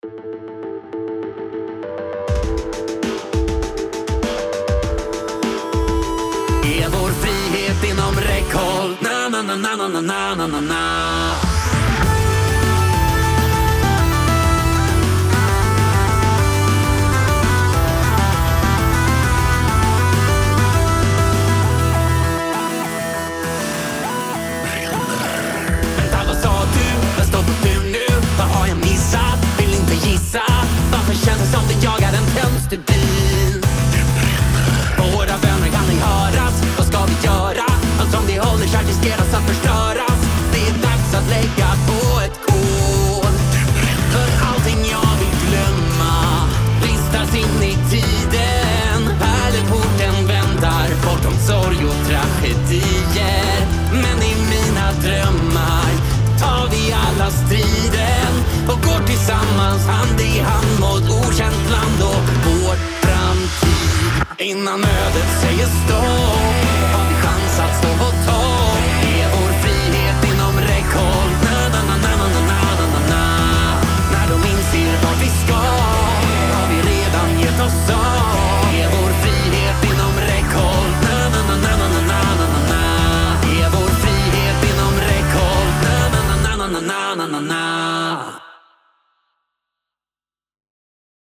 Caution: Loud
Mixed & Mastered